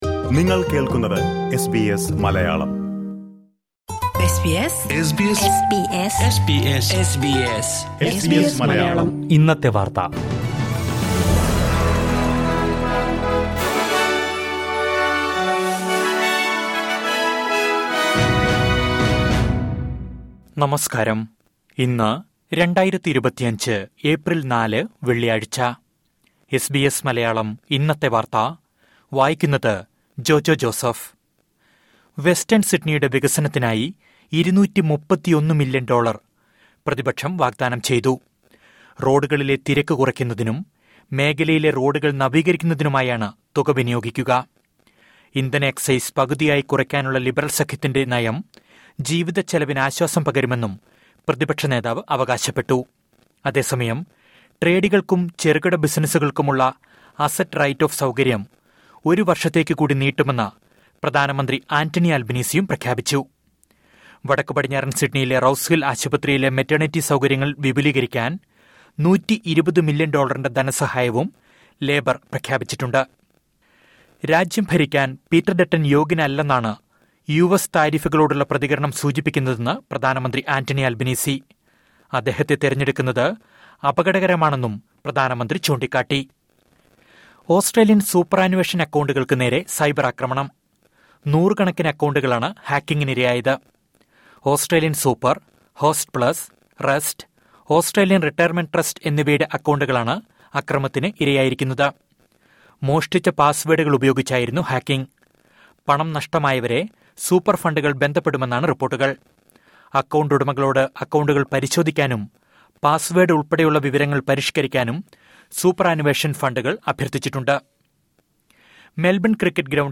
2025 ഏപ്രിൽ നാലിലെ ഓസ്‌ട്രേലിയയിലെ ഏറ്റവും പ്രധാന വാര്‍ത്തകള്‍ കേള്‍ക്കാം...